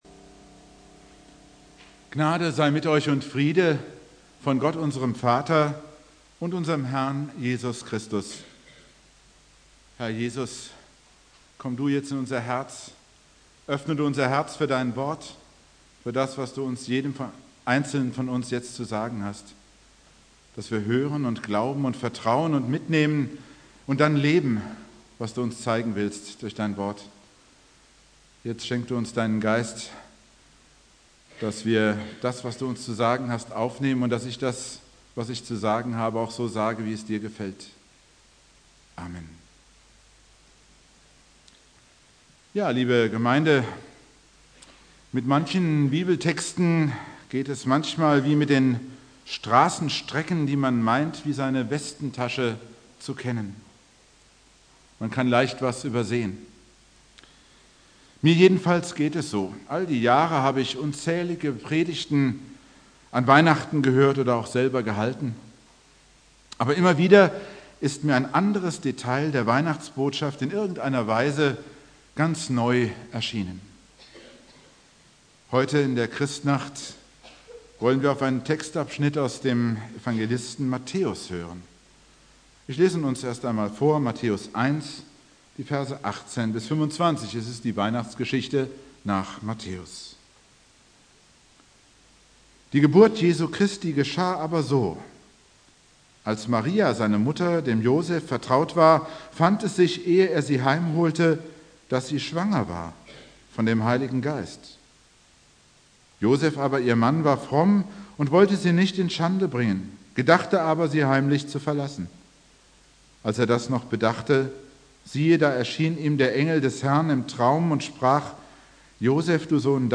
Predigt
Heiligabend 23 Uhr